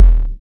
1710R BD.wav